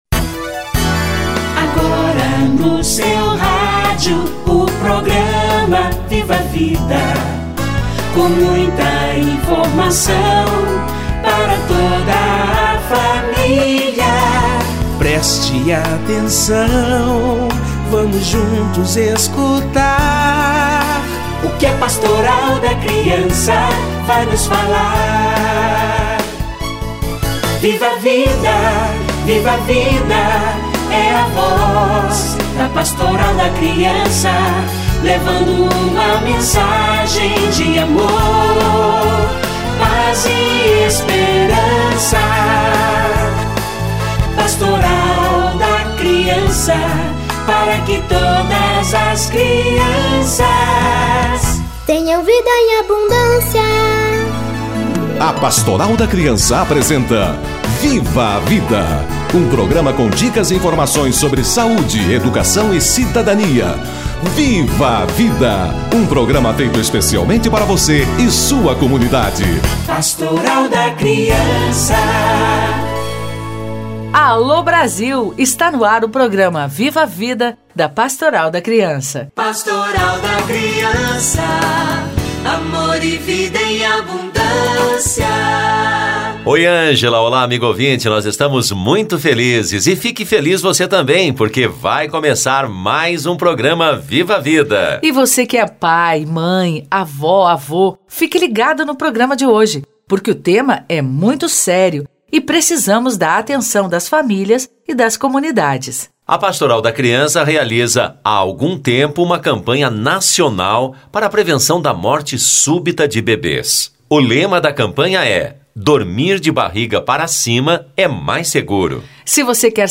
Dormir de barriga pra cima é mais seguro - Entrevista